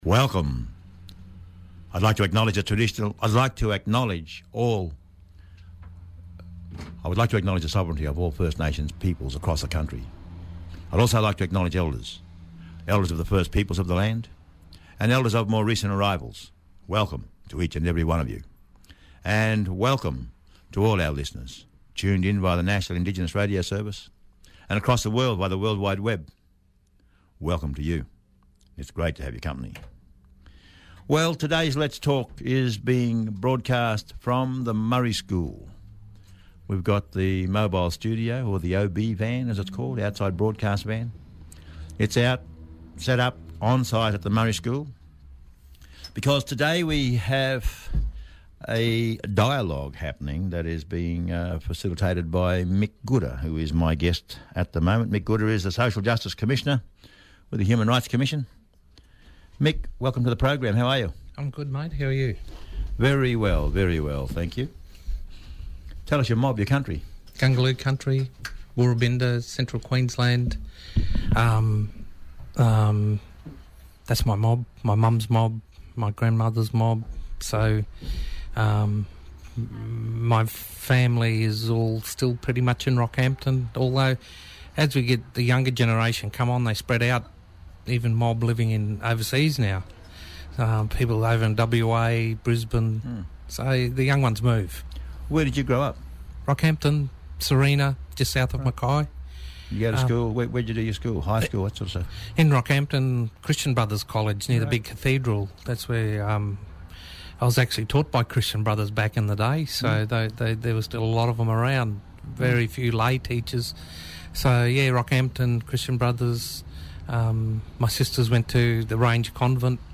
Live Broadcast from the UN Declaration for the Rights of Indigenous Peoples Dialogue
Mick Gooda – Human Rights Commisioner